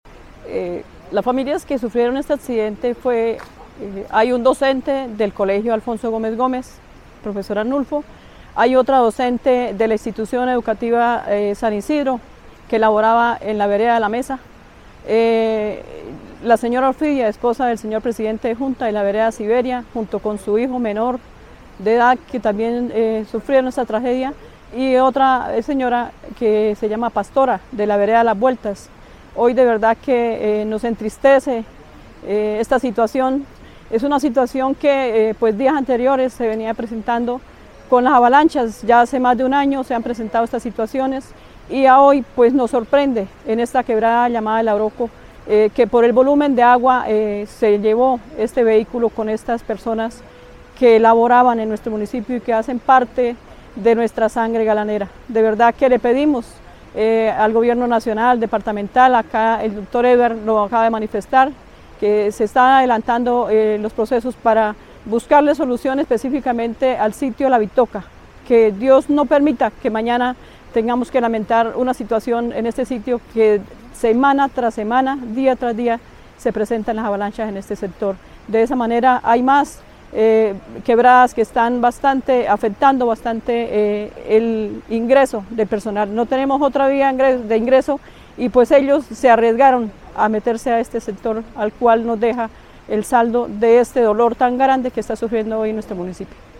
Sofía Medina Serrano, alcaldesa de Galán